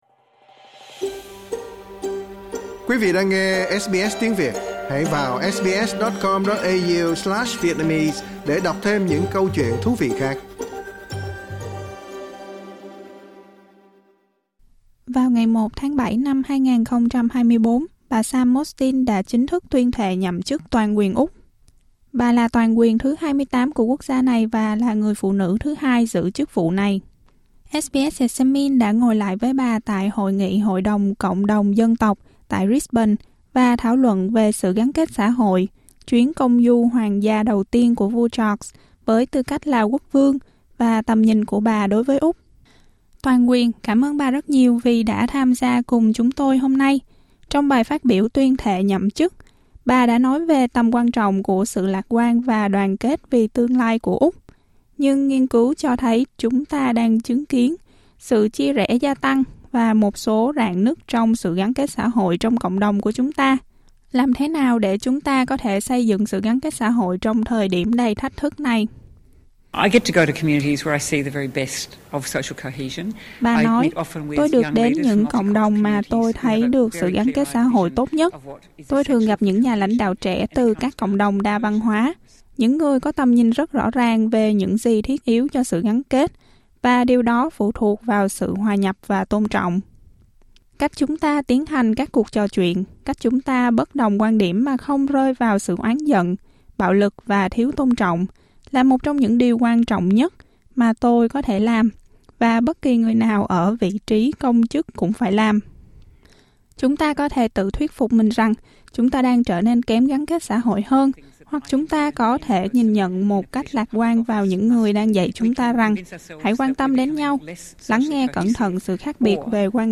Trong một cuộc phỏng vấn với SBS Examines, bà Sam Mostyn đã chia sẻ suy nghĩ của mình về sự gắn kết xã hội, sự lạc quan và vai trò của chế độ quân chủ.